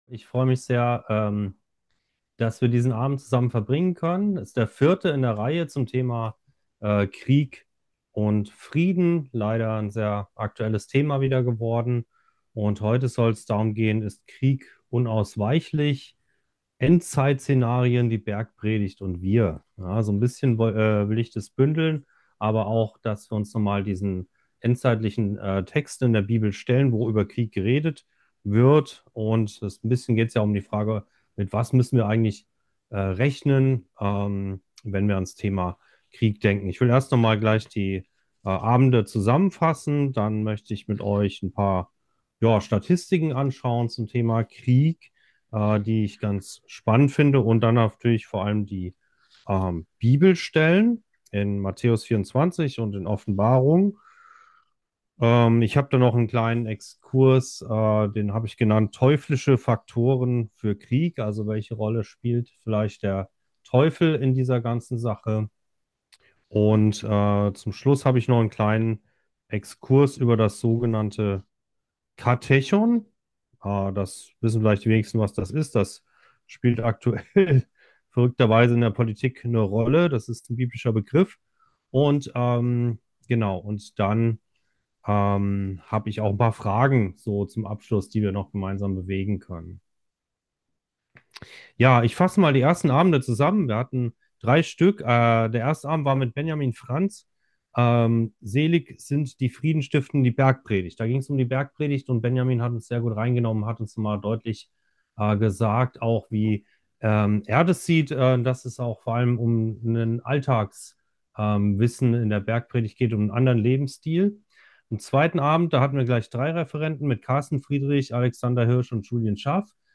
Inmitten einer angespannten Weltlage ist es wichtig auszuloten, was die Bibel zu „Krieg und Frieden“ sagt und wie wir unser Verhalten daran ausrichten können. Ausgehend von der Bergpredigt bieten wir dazu vier AKD-Themenabende per Zoom an. 4.